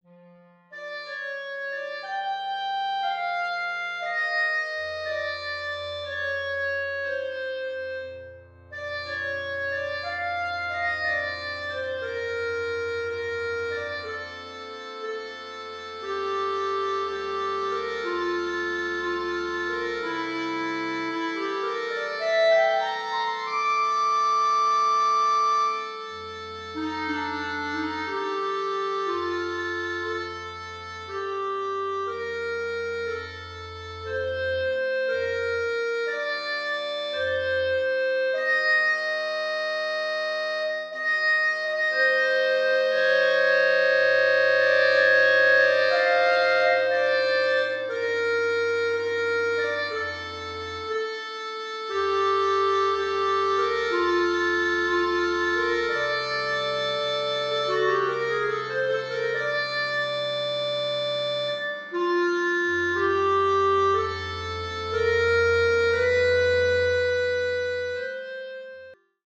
Klarinettenquartett/Saxophonquartett